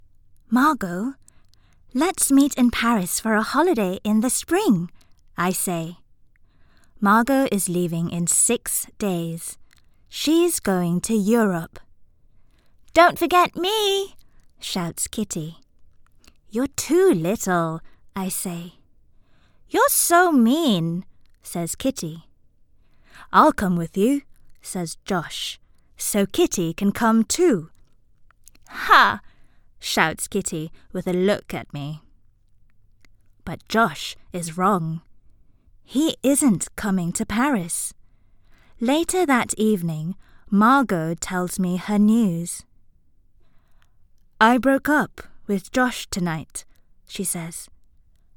Malay Voices
Malay, Female, Home Studio, 20s-30s